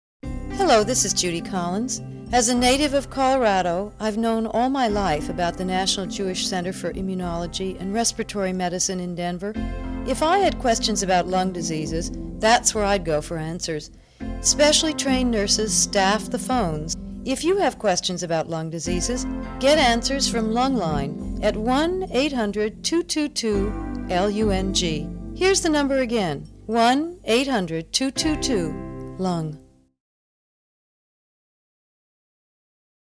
Celebrity PSAs